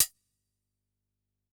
Index of /musicradar/Kit 17 - Electro
CYCdh_ElecK07-ClHat02.wav